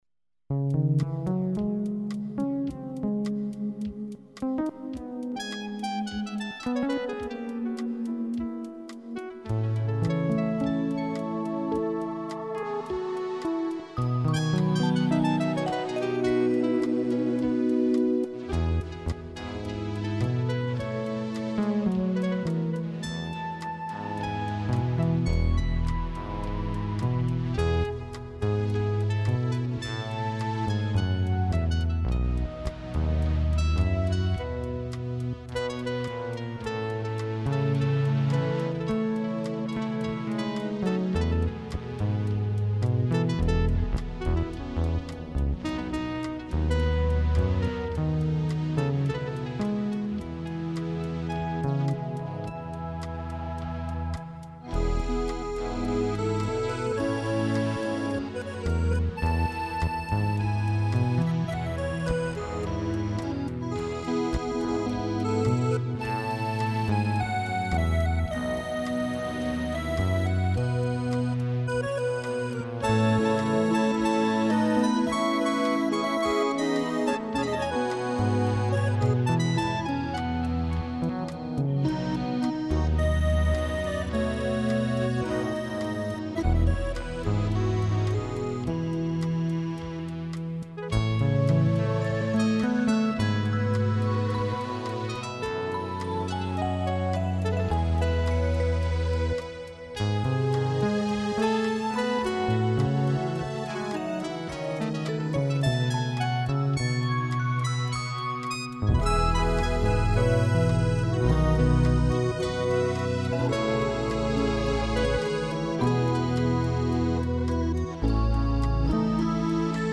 Slow To Moderate Dance 4.64 MB